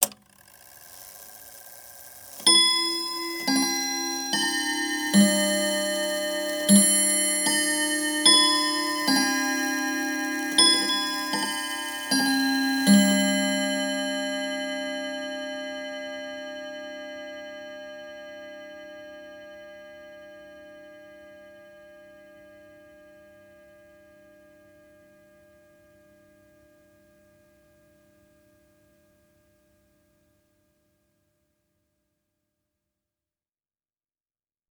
Clock Three-Quarter Hour Chime.wav